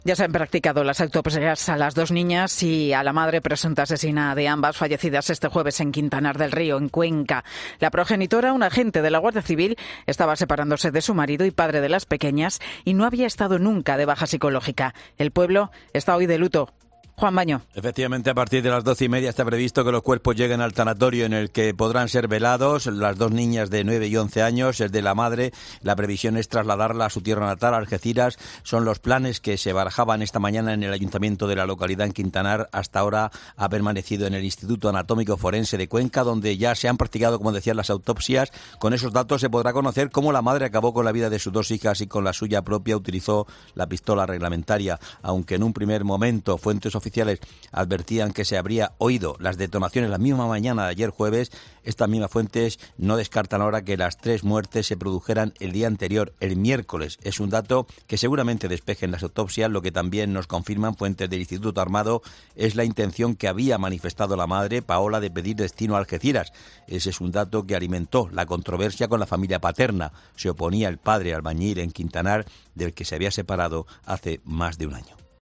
La autopsia determinará si el parricidio de Quintanar del Rey ocurrió el miércoles. Crónica